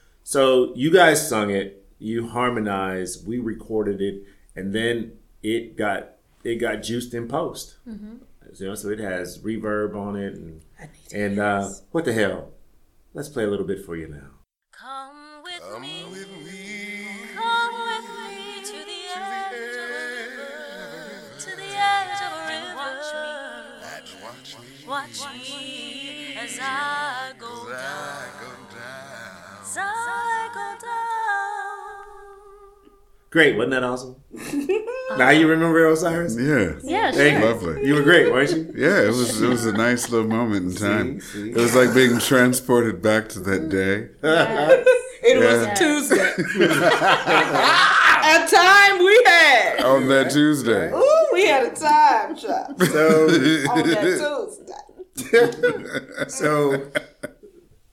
In this Backstage episode, members of the cast from the original production and podcast play come together to take a look at the cultural landscape of the play and it’s signature song: Come With Me, and the serendipity of theater.